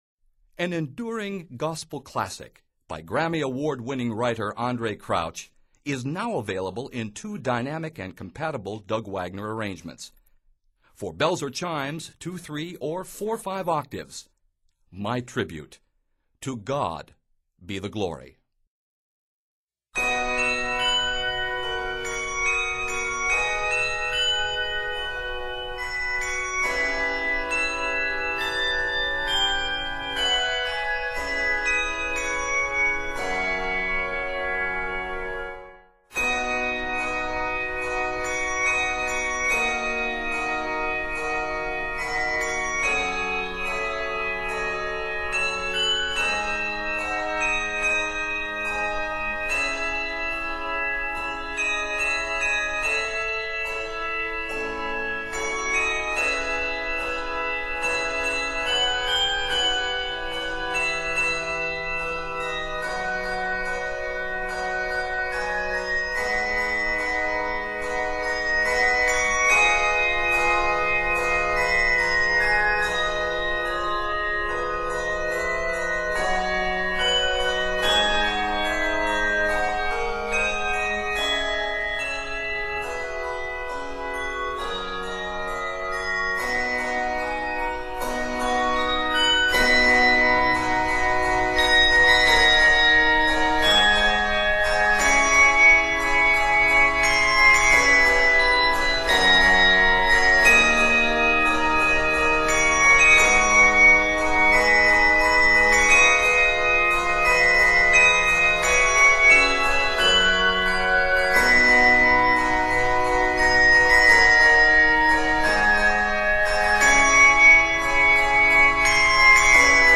Set in C Major, this work is 55 measures.